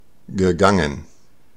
Ääntäminen
Ääntäminen Tuntematon aksentti: IPA: /gəˈgaŋən/ Haettu sana löytyi näillä lähdekielillä: saksa Käännöksiä ei löytynyt valitulle kohdekielelle. Gegangen on sanan gehen partisiipin perfekti.